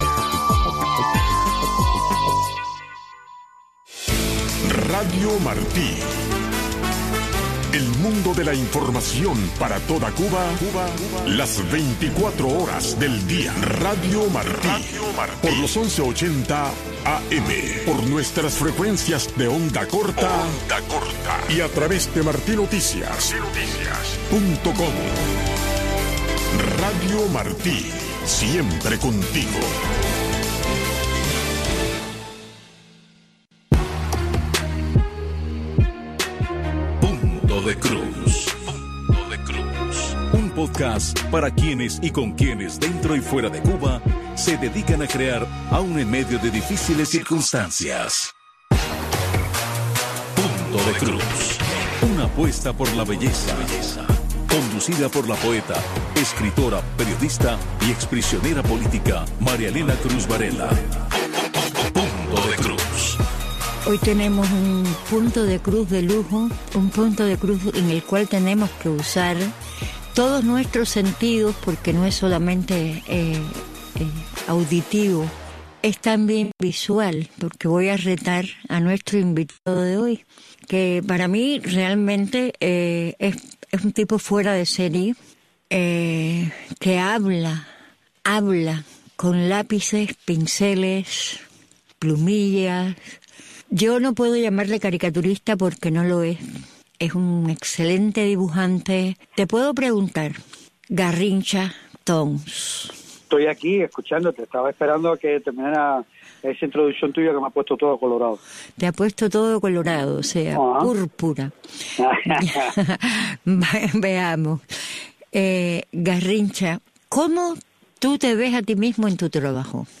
En este espacio informativo de Radio Martí escuche de primera mano los temas que impactan el día a día de los cubanos dentro de la Isla. Voces del pueblo y reportes especiales convergen para ofrecerle una mirada clara, directa y actual sobre la realidad cubana.